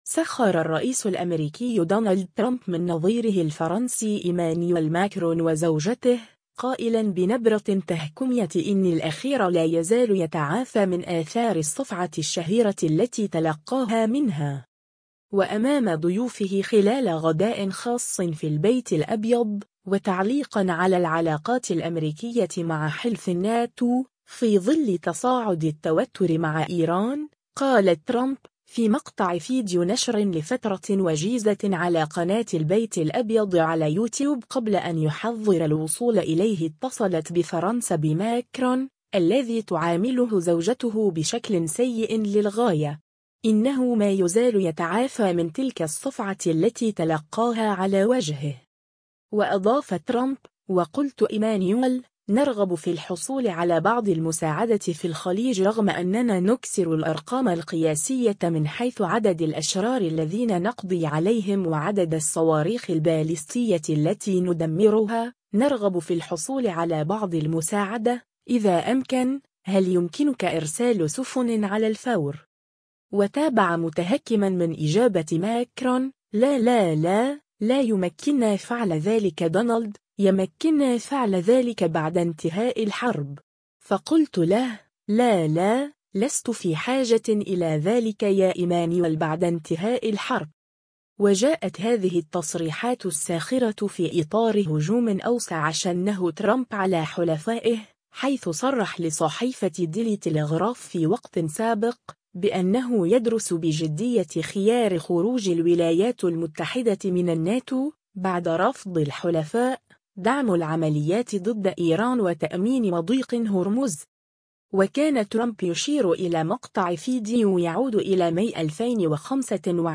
سخر الرئيس الأمريكي دونالد ترامب من نظيره الفرنسي إيمانويل ماكرون وزوجته، قائلا بنبرة تهكمية إن الأخير “لا يزال يتعافى” من آثار الصفعة الشهيرة التي تلقاها منها.
وأمام ضيوفه خلال غداء خاص في البيت الأبيض، وتعليقا على العلاقات الأمريكية مع حلف “الناتو”، في ظل تصاعد التوتر مع إيران، قال ترامب، في مقطع فيديو نشر لفترة وجيزة على قناة البيت الأبيض على “يوتيوب” قبل أن يحظر الوصول إليه: ” اتصلت بفرنسا بماكرون، الذي تعامله زوجته بشكل سيئ للغاية.. إنه مايزال يتعافى من تلك الصفعة التي تلقاها على وجهه”.